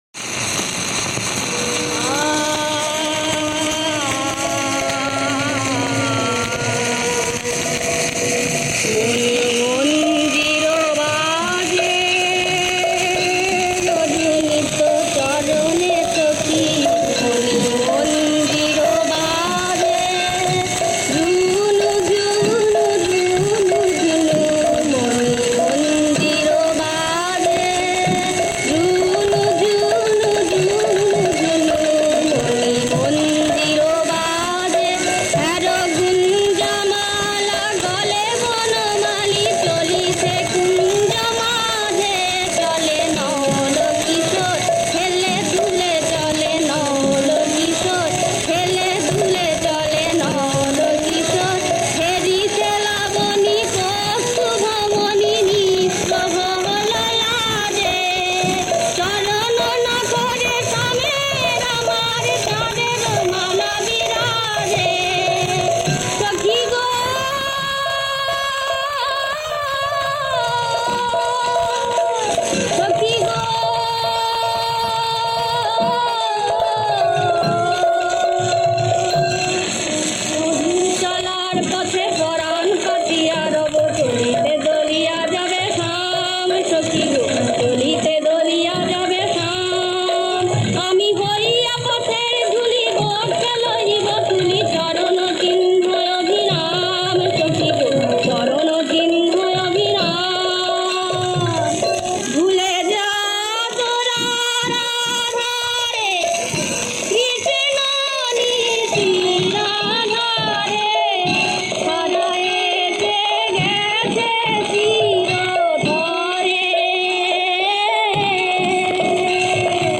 বেহাগ মিশ্র-কার্ফা]।